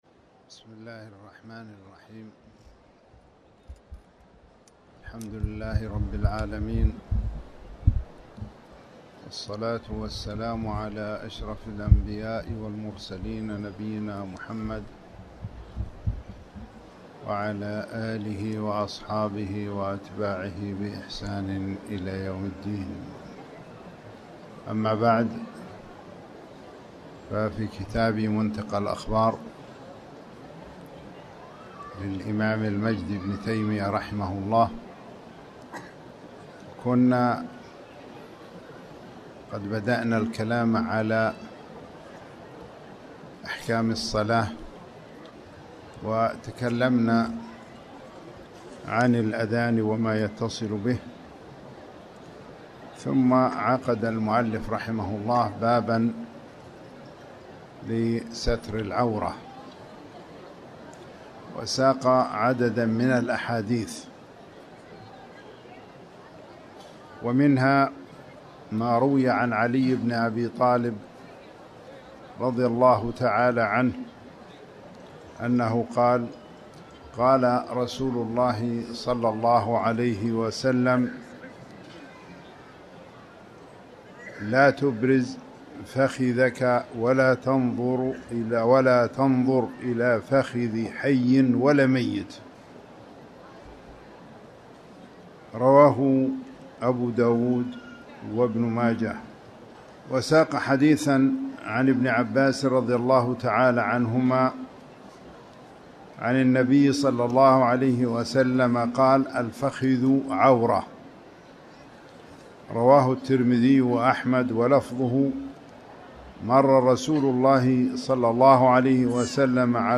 تاريخ النشر ١٩ ربيع الأول ١٤٤٠ هـ المكان: المسجد الحرام الشيخ